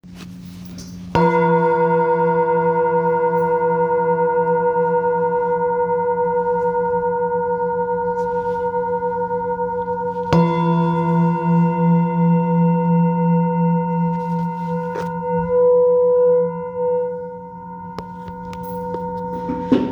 Singing Bowl, Buddhist Hand Beaten, with Fine Etching Carving
Material Seven Bronze Metal